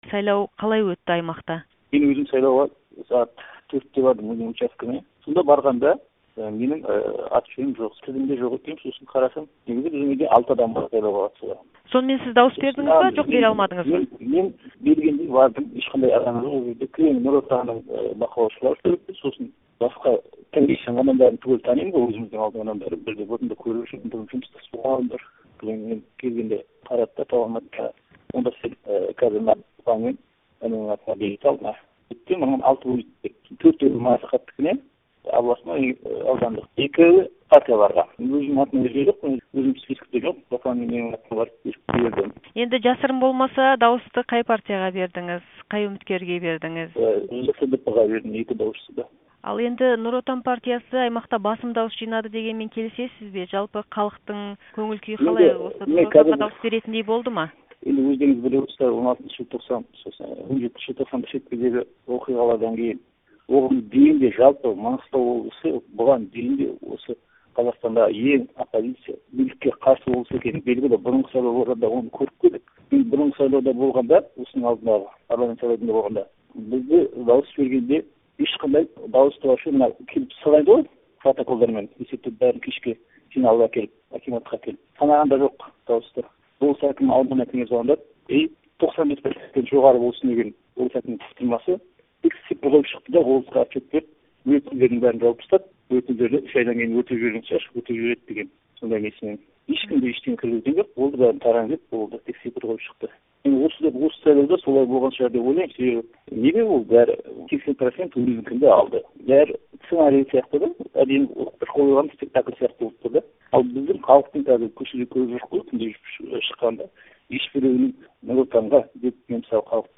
Шетпе тұрғынының сайлауға қатысты сұқбаты